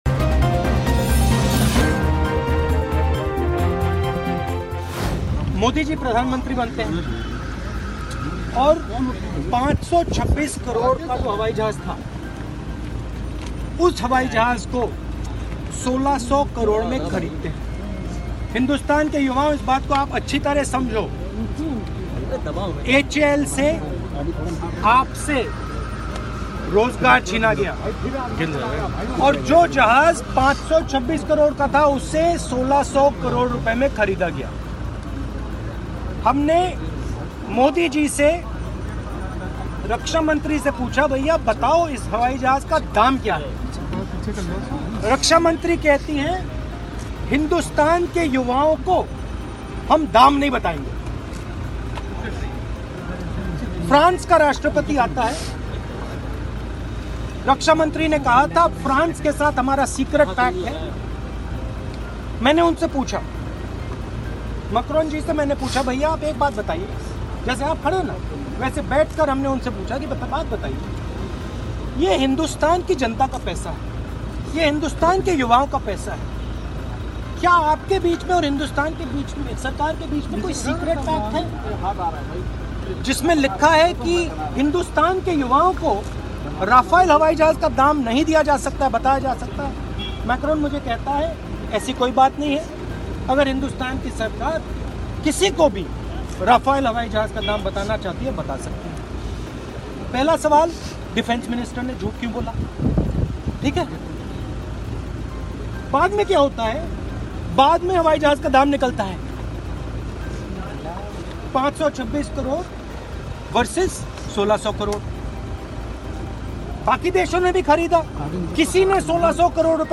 न्यूज़ रिपोर्ट - News Report Hindi / राहुल गाँधी ने फिर प्रधानमंत्री नरेंद्र मोदी का कहा "चोर "